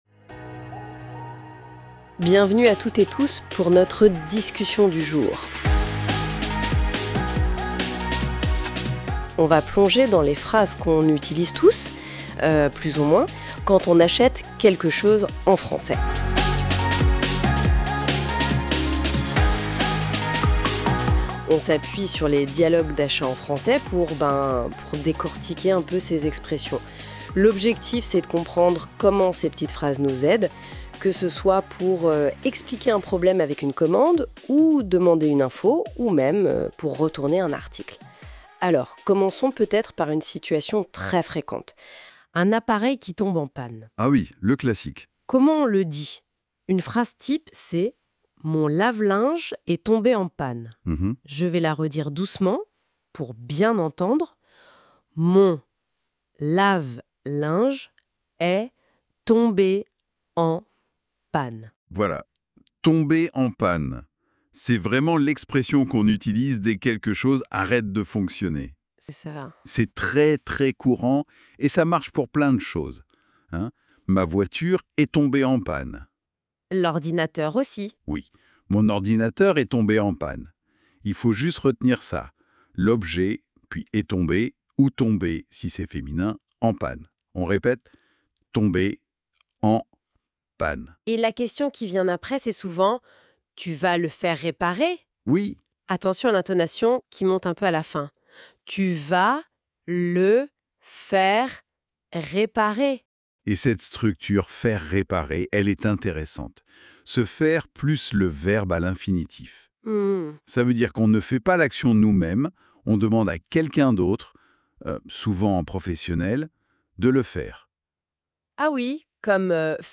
Apprenez le français avec un dialogue pratique + PDF.